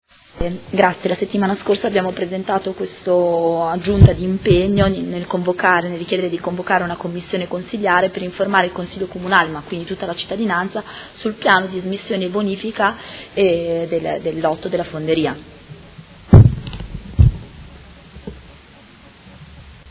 Seduta del 22/11/2018. Presenta Emendamento Prot. Gen. n. 17971 a Ordine del Giorno presentato dai Consiglieri Scardozzi, Rabboni, Fantoni e Bussetti (M5S) avente per oggetto: Richiesta centralina monitoraggio Madonnina